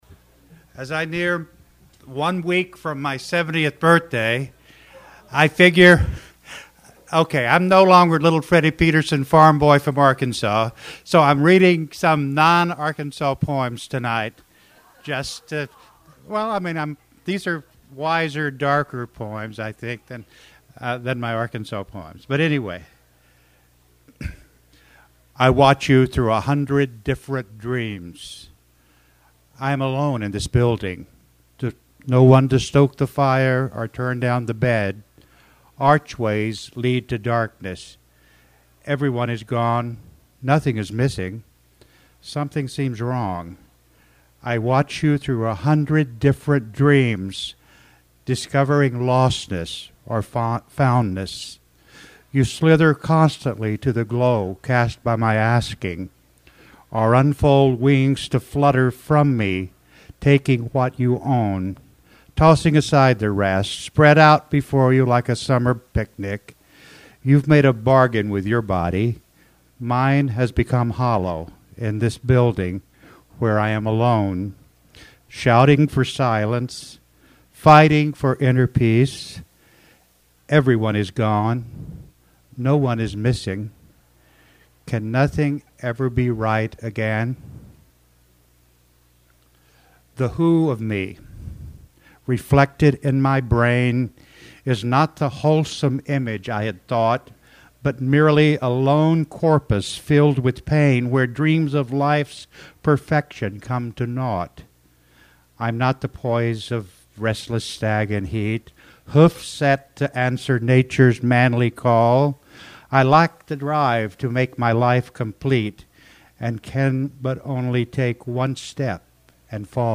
Hemingway’s Summer Poetry Series